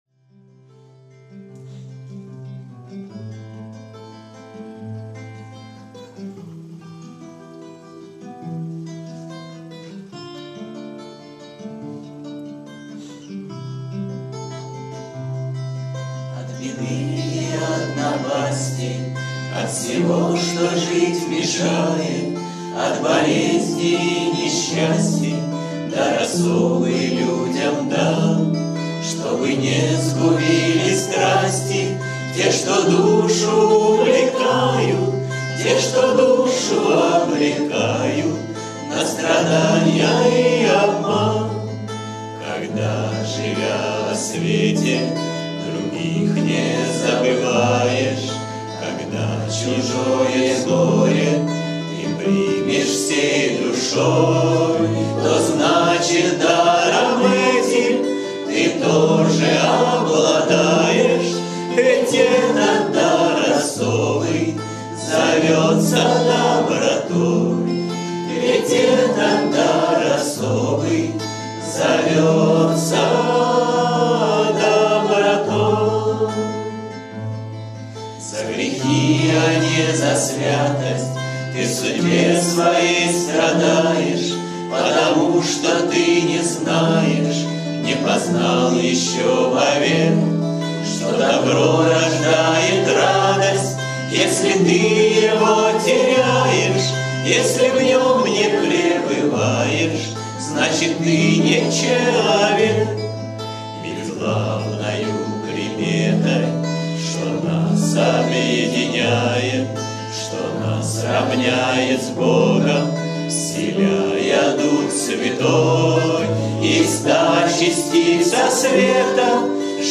кавер-версия
Песни у костра